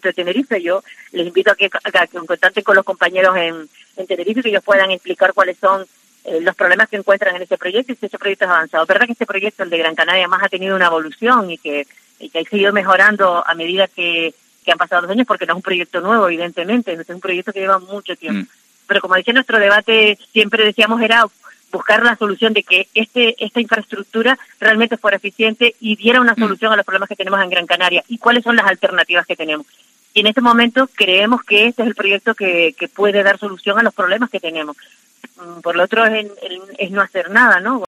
Conchi Monzón, consejera de Sí Podemos y vicepresidenta tercera del Cabildo de Gran Canaria
Conchi Monzón, consejera de Sí Podemos y vicepresidenta tercera del ejecutivo insular, ha relatado en los micrófonos de COPE Canarias que no conoce la realidad del territorio de Tenerife, ante la negativa de sus compañeros de partido en esa isla, por el proyecto de la construcción del tren.